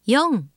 ホームページ作成で利用できる、さまざまな文章や単語を、プロナレーターがナレーション録音しています。
ナレーション：